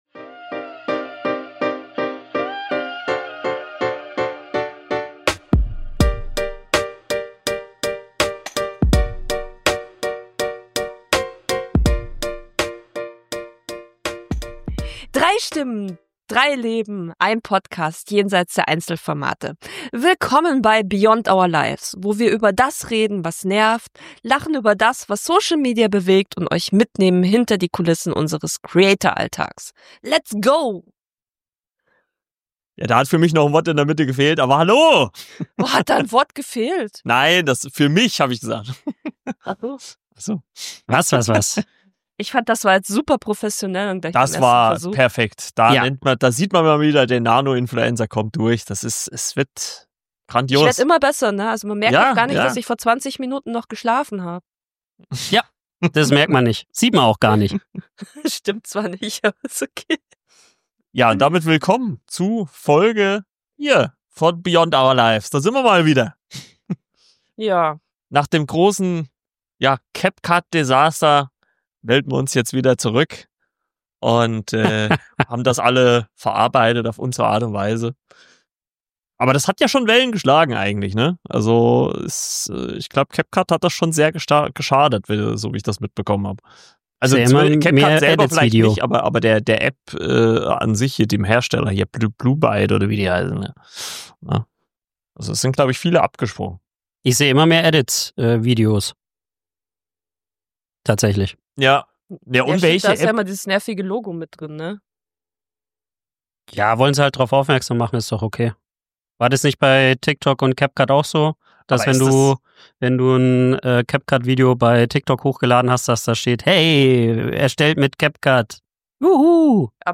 Drei Hosts im Gespräch